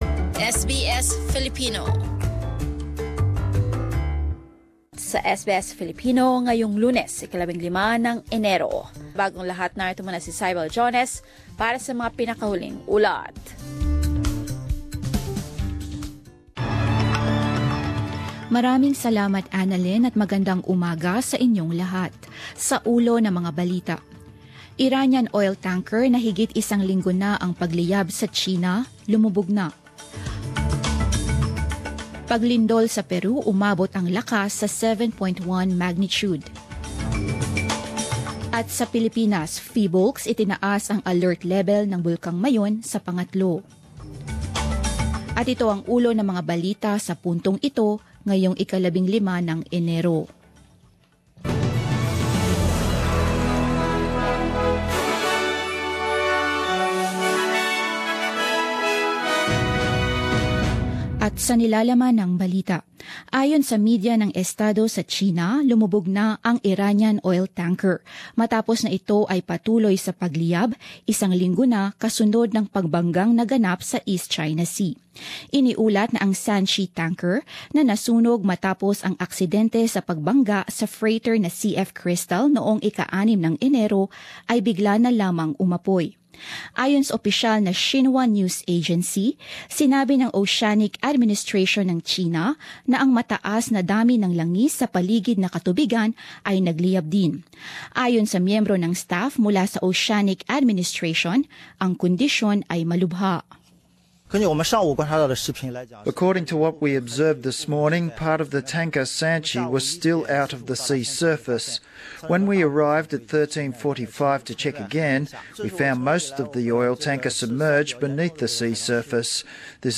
10 am News Bulletin